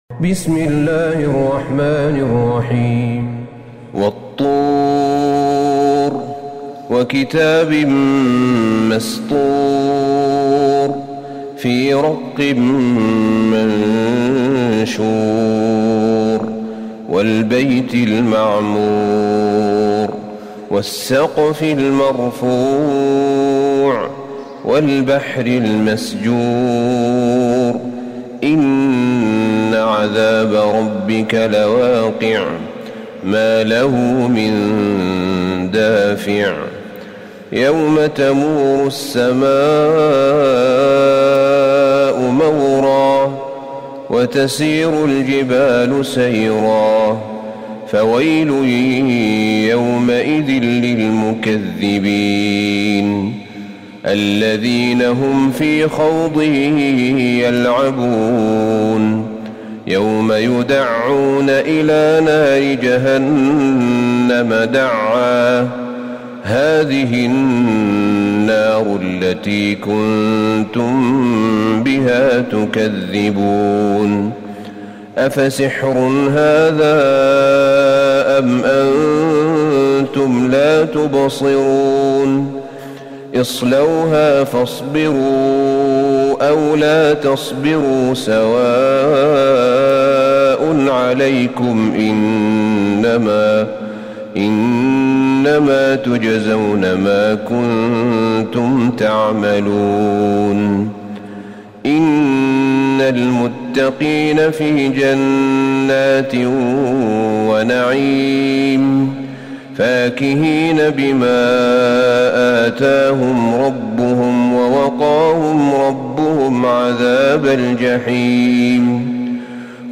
سورة الطور Surat AtTur > مصحف الشيخ أحمد بن طالب بن حميد من الحرم النبوي > المصحف - تلاوات الحرمين